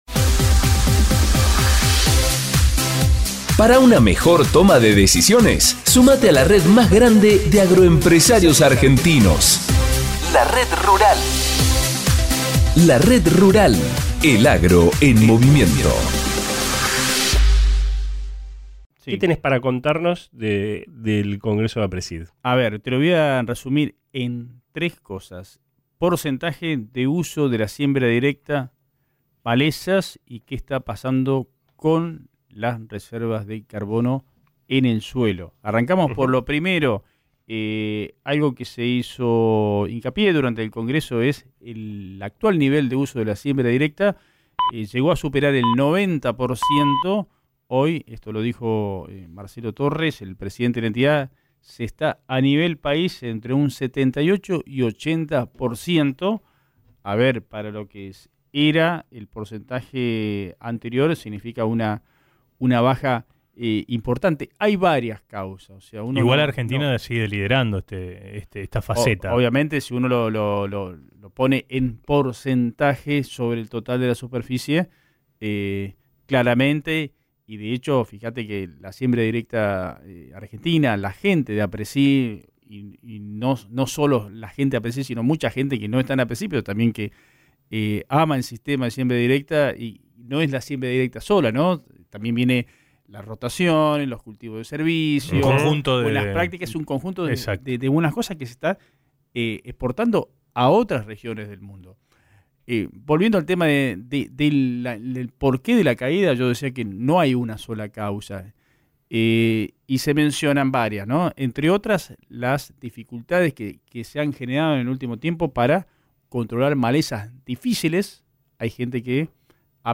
Y parece que habla con tanto cuidado!